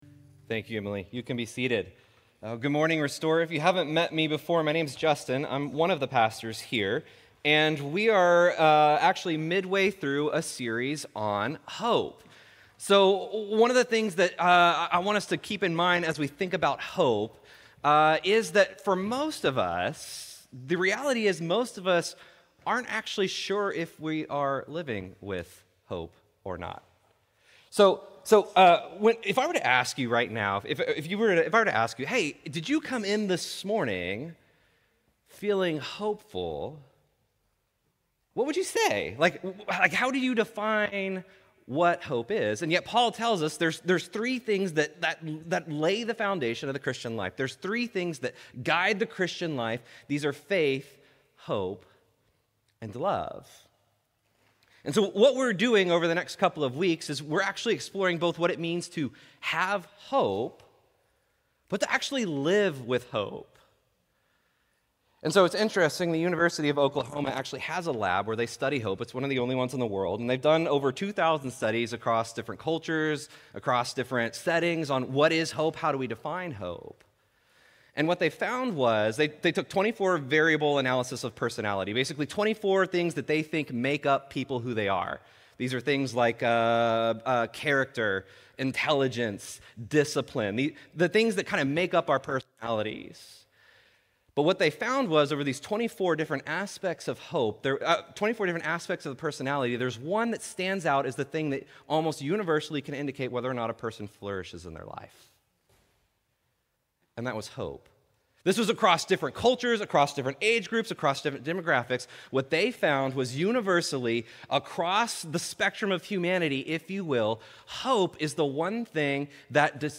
Restore Houston Church Sermons You Can't Outrun God's Love Oct 21 2024 | 00:25:55 1x 00:00 / 00:25:55 Subscribe Share Apple Podcasts Overcast RSS Feed Share Link Embed